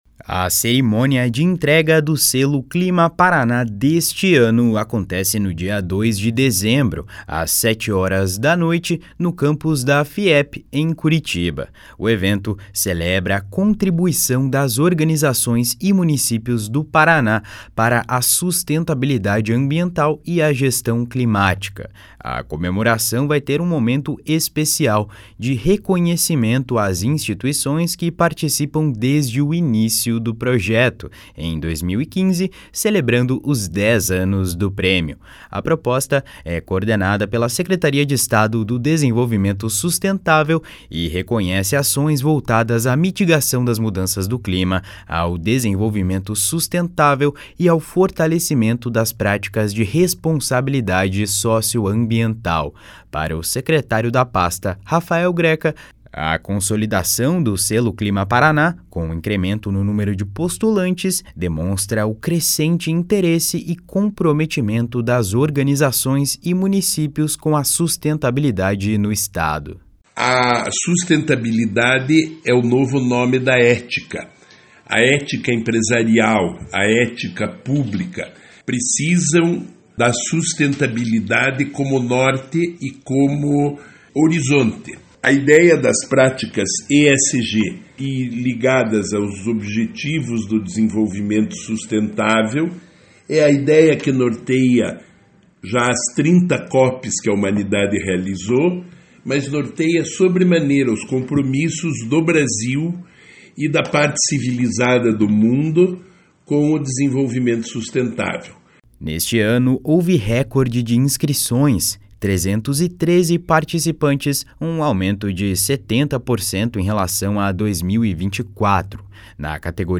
// SONORA RAFAEL GRECA //